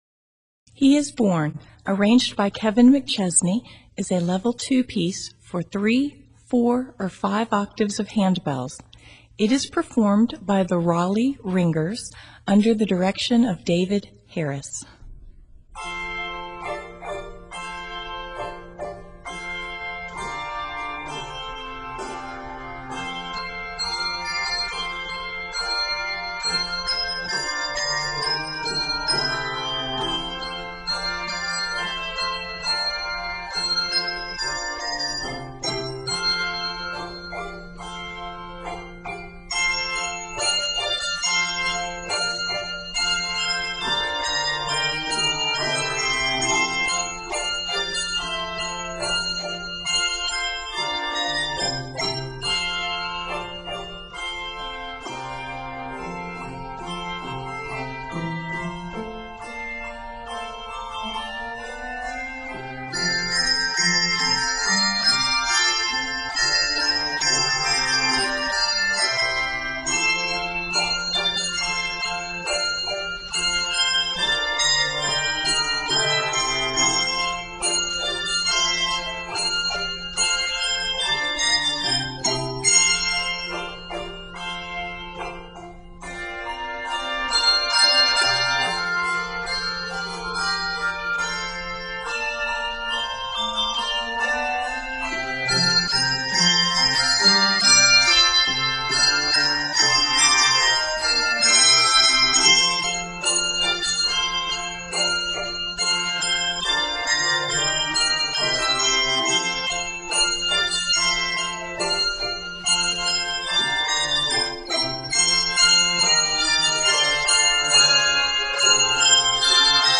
the old French carol, and give the effect of a hand drum.